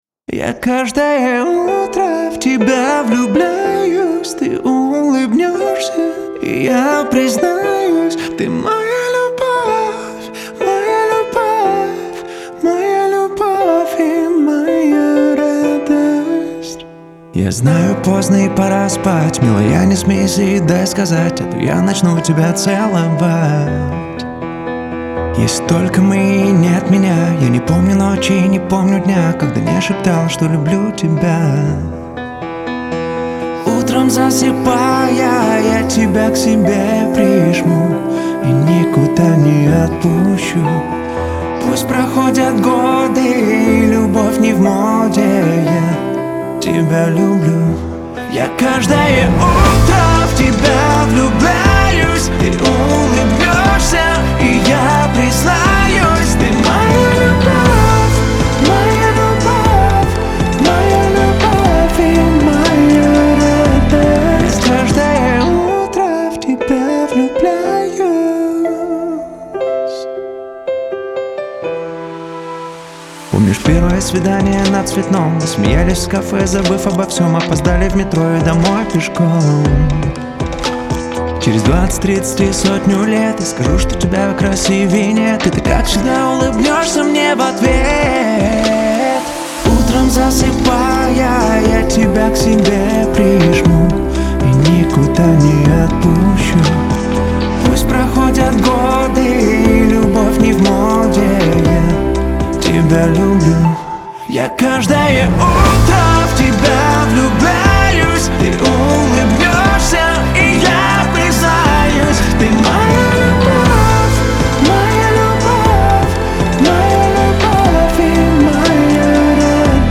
романтическая поп-песня